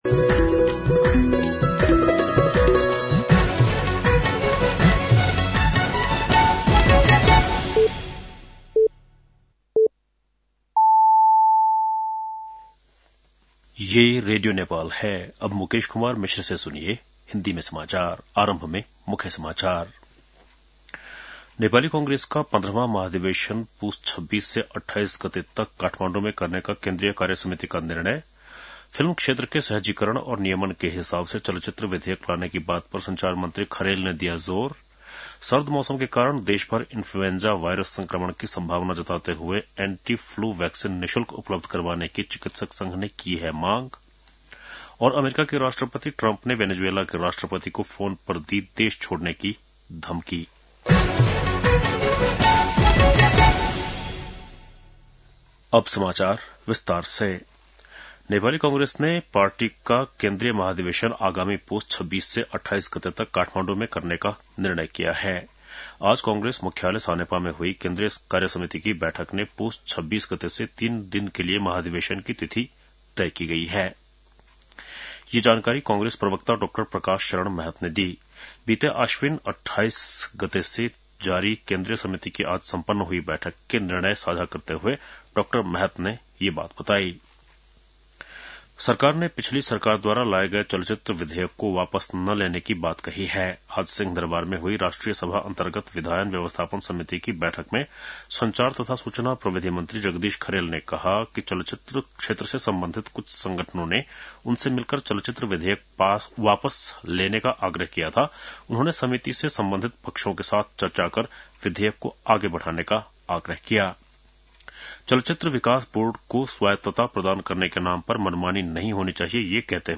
बेलुकी १० बजेको हिन्दी समाचार : १५ मंसिर , २०८२
10-PM-Hindi-NEWS-8-15.mp3